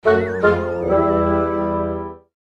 На этой странице собраны звуки грустного тромбона (sad trombone) — узнаваемые меланхоличные мотивы, часто используемые в кино и юмористических роликах.
Грустный тромбон – звук неудачи